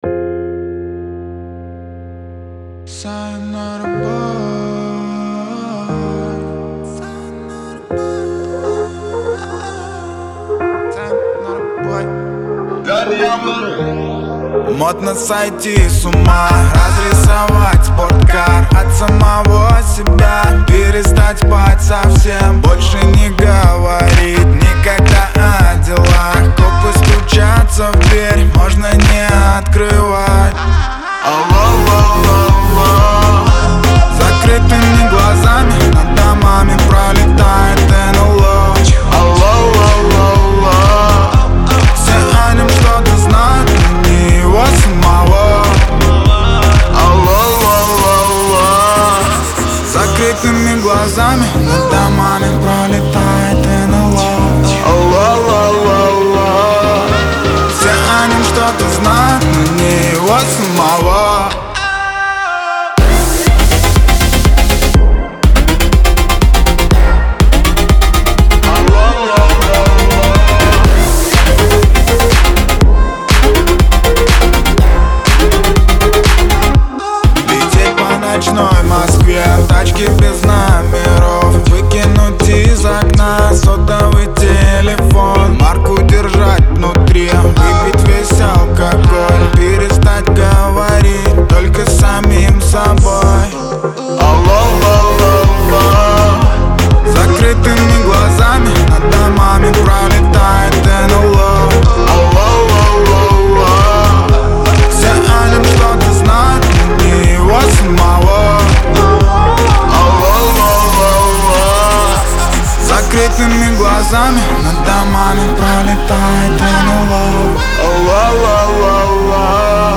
Стиль: Dance / Electronic / House / Pop